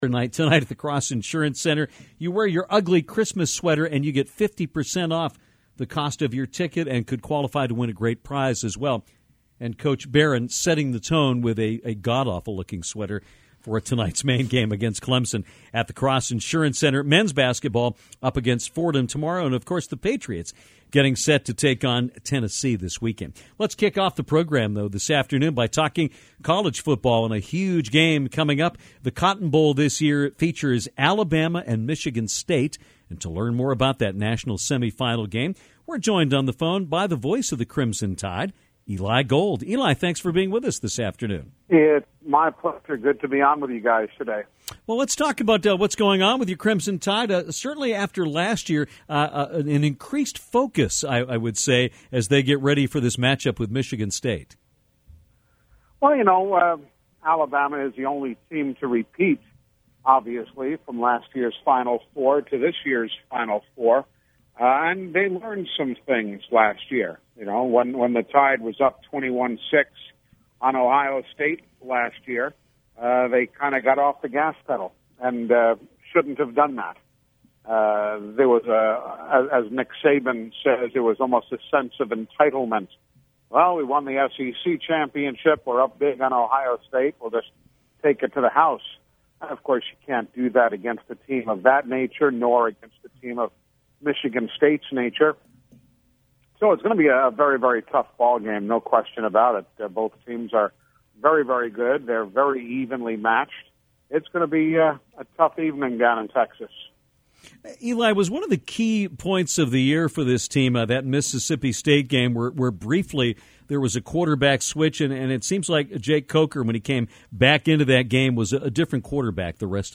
Alabama football play by play voice Eli Gold joined Downtown to talk about the upcoming FBS College Football Playoff. He talked about the strong play on both sides of the ball for the Tide and also broke down the efforts of QB Jake Coker.